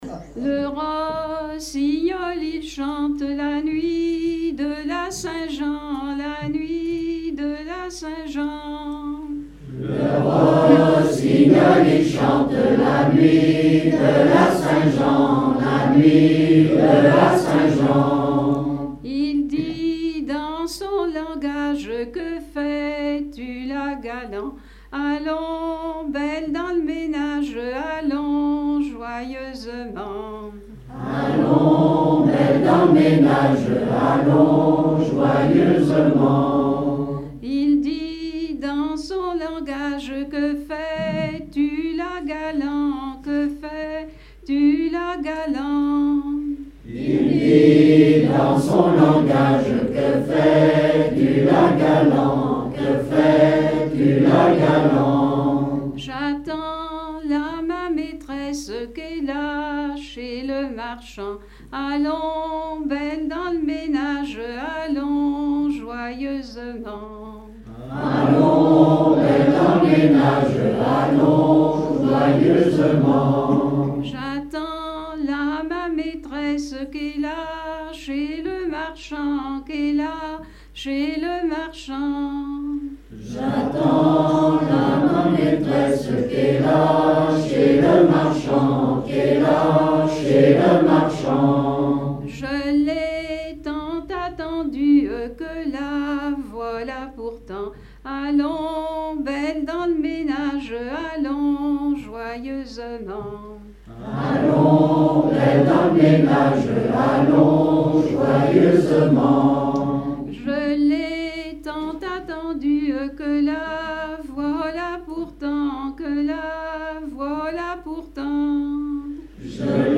Veillée (version Revox)
Pièce musicale inédite